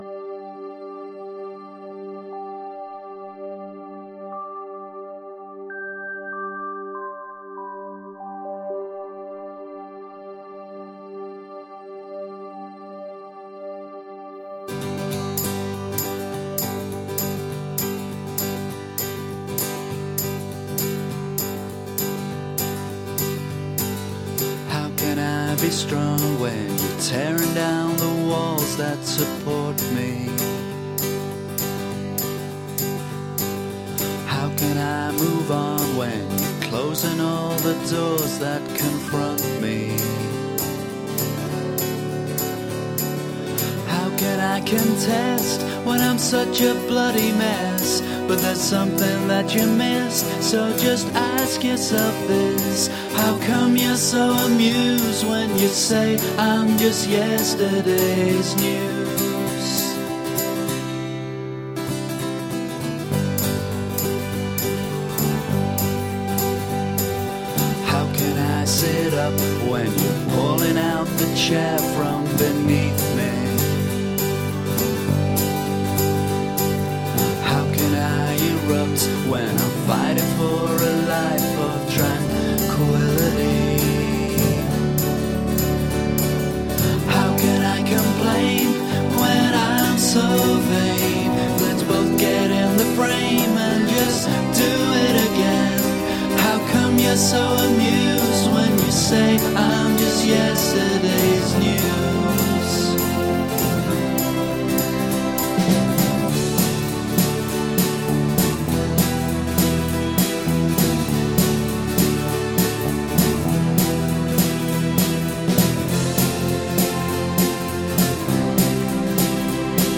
Shimmering indie guitar pop with orchestral moments.
The mix slowly builds up as more instruments are introduced.
Tagged as: Alt Rock, Pop, Folk-Rock, Vocal, Electric Guitar